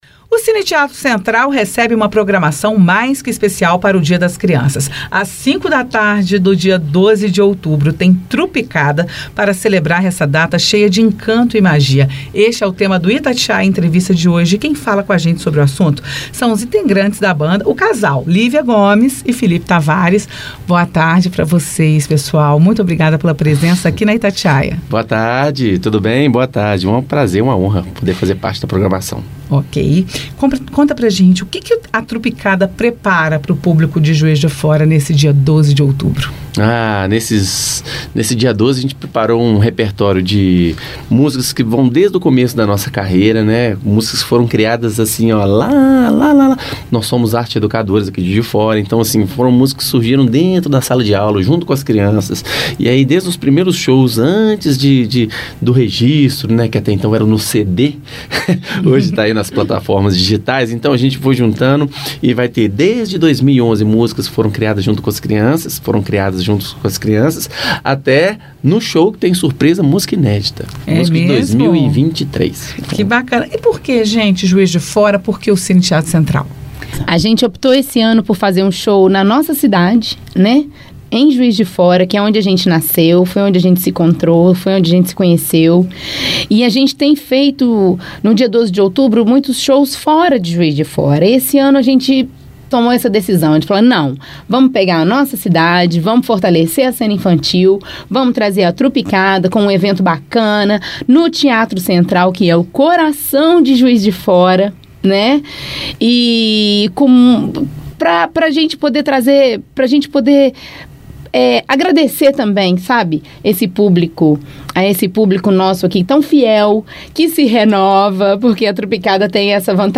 1010-Itatiaia-Entrevista-Trupicada.mp3